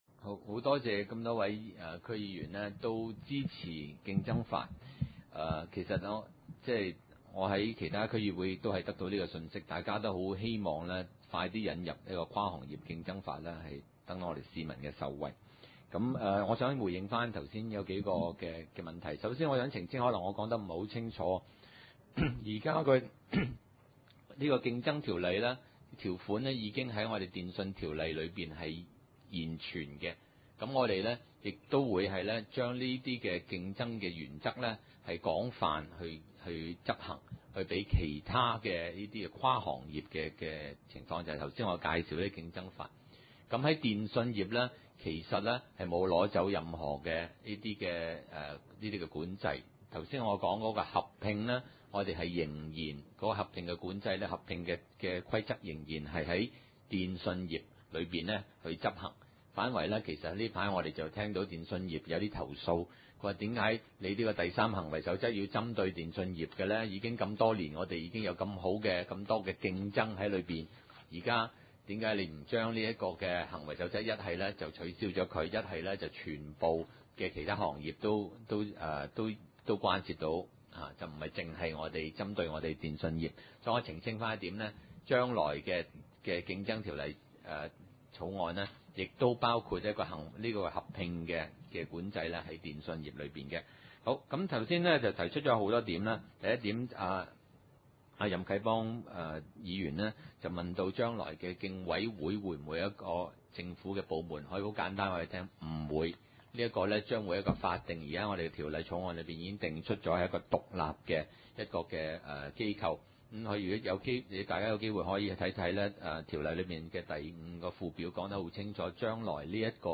大埔區議會 2011年度第二次會議 日期：2011年3月1日 (星期二) 時間：上午9時30分 地點：大埔區議會秘書處會議室 議 程 討論時間 I. 競爭條例草案簡介 28:28 ( 大埔區議會文件 12/2011 號 ) 20:52 19:30 II.